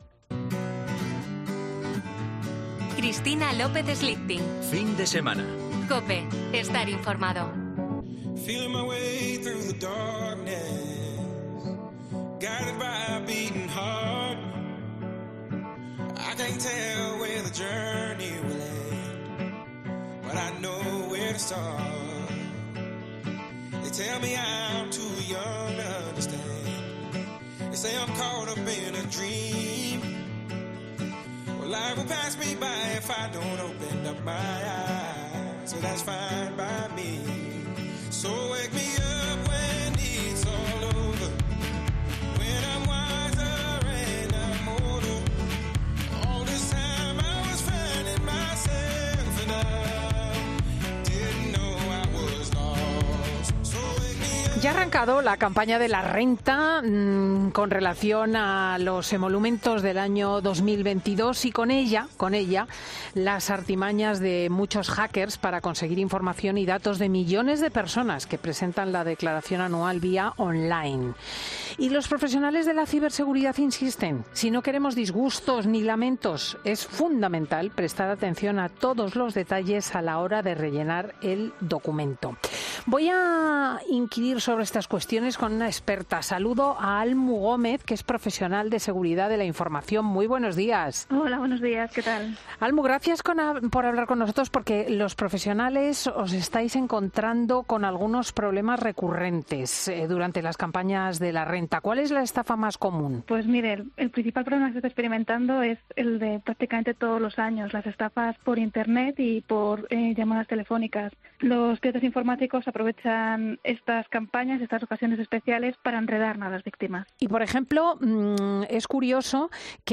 explica con Cristina López Schlichting en 'Fin de Semana' los fraudes más habituales de...